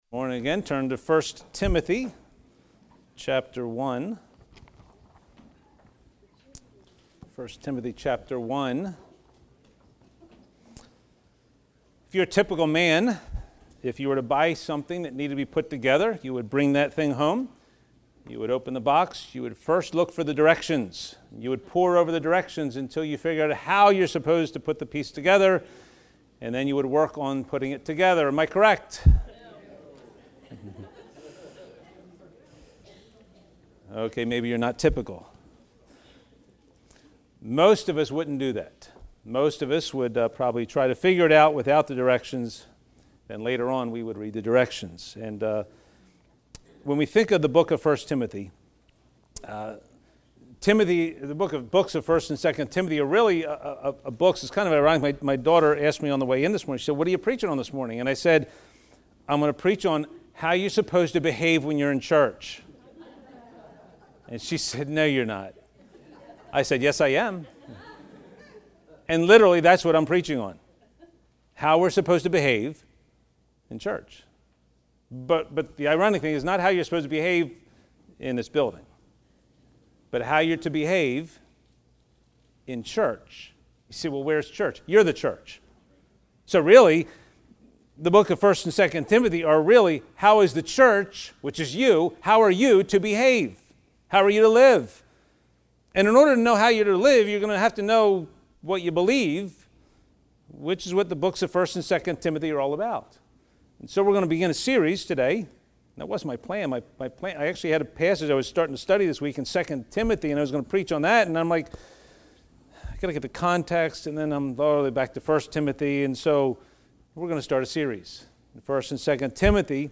Sermons - Bethel Bible Baptist Church